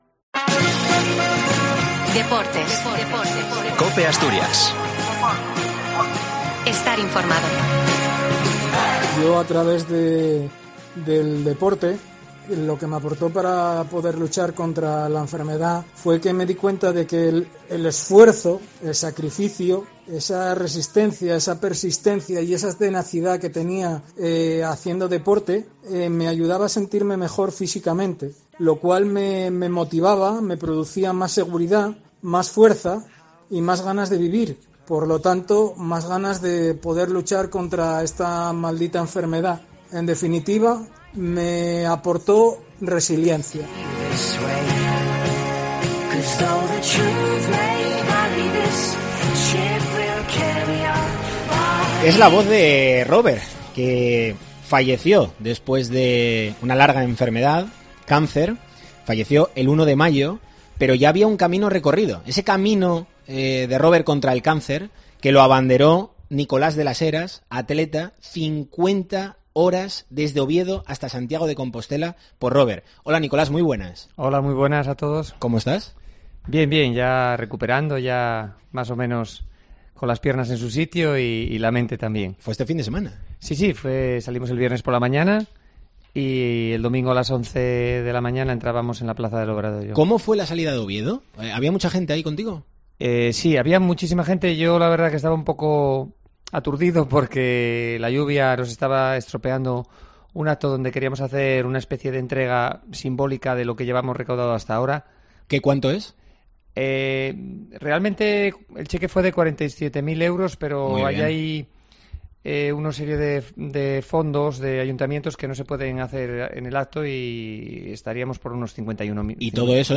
ha vuelto en este programa especial desde ADARSA (Siero) para explicar cómo ha sido la experiencia y el resultado del 'Camino'.